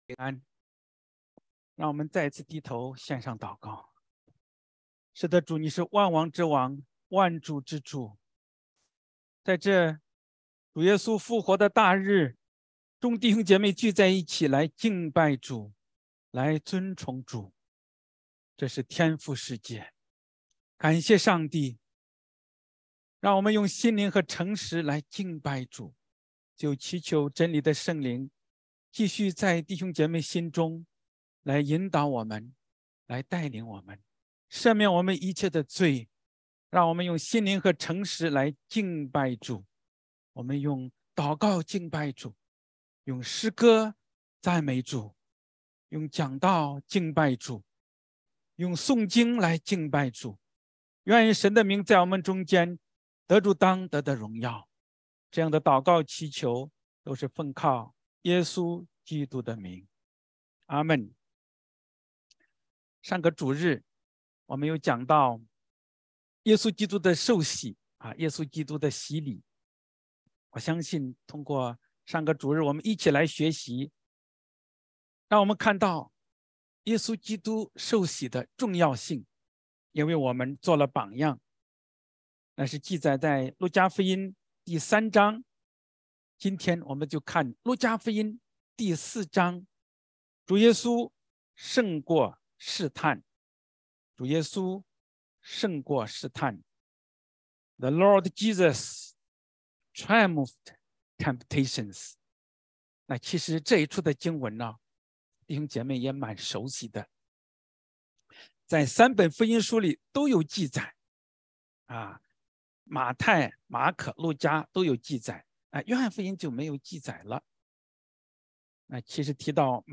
Sermon